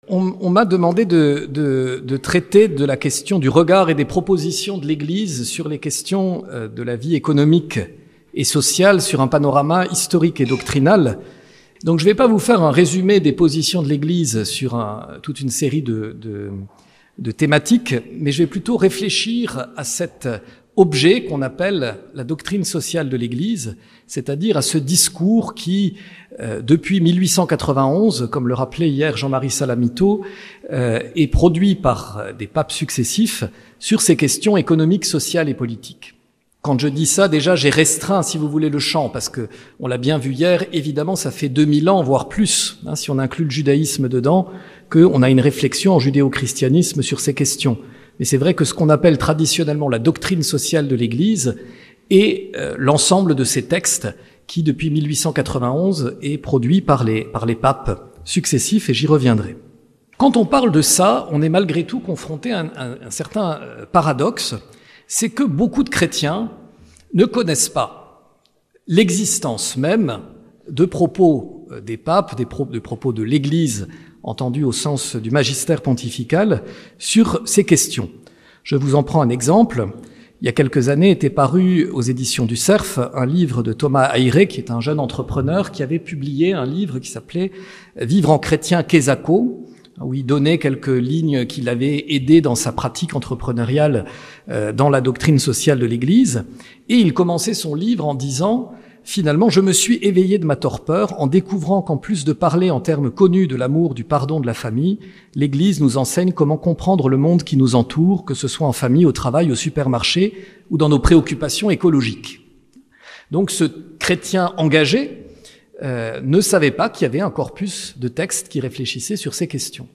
Ste Baume. Université d'été